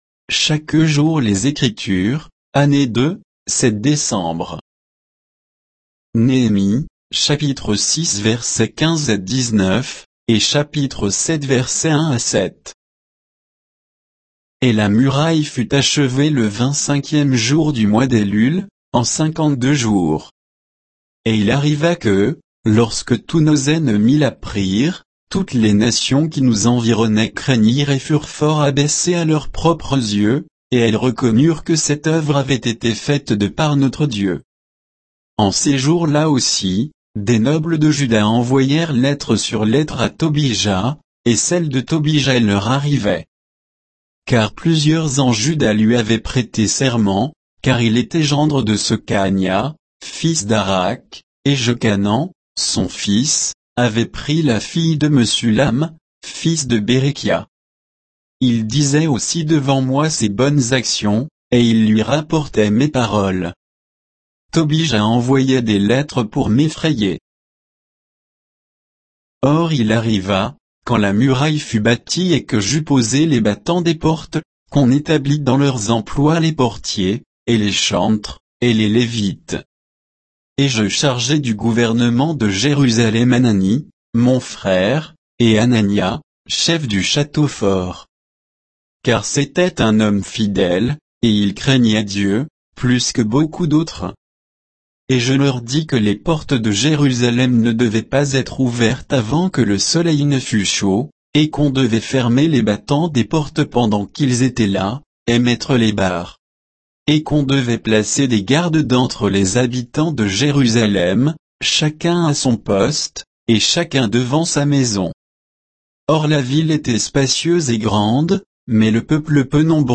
Méditation quoditienne de Chaque jour les Écritures sur Néhémie 6, 15 à 7, 7